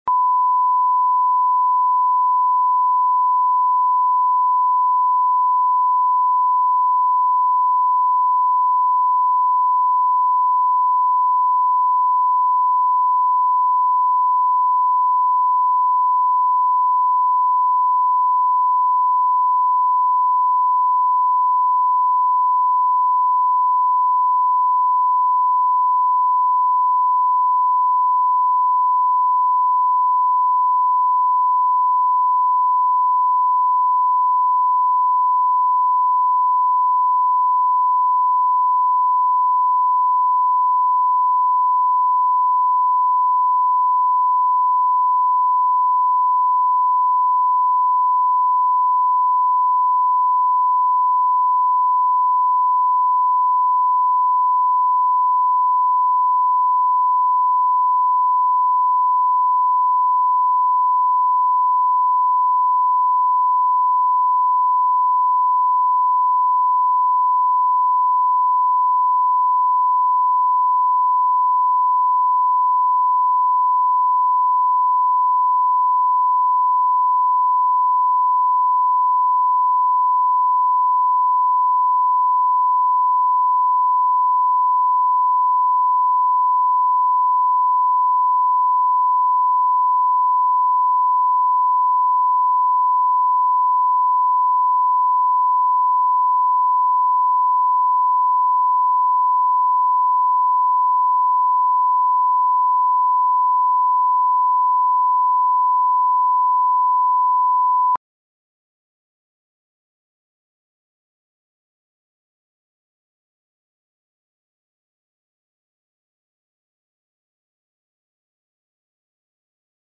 Аудиокнига Сорок пять | Библиотека аудиокниг